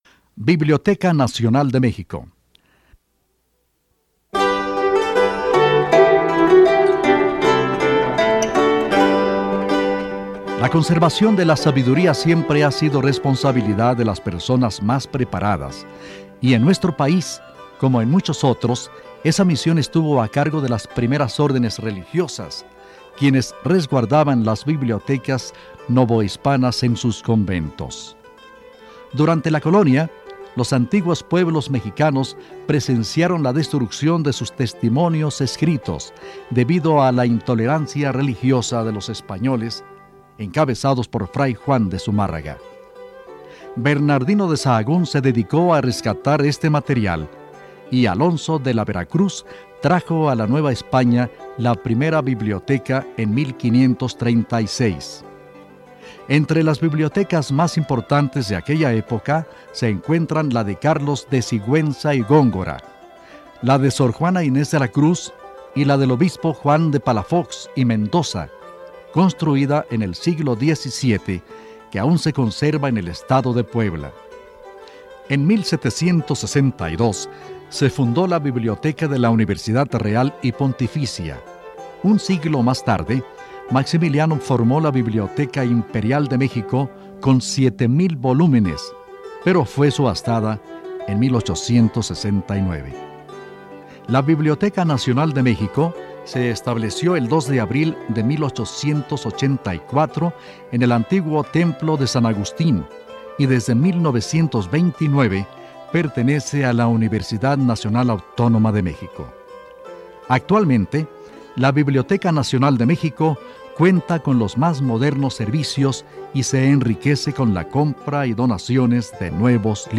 Escucha un comentario sobre La Biblioteca Nacional en el programa "Cápsulas institucionales", transmitido en 2001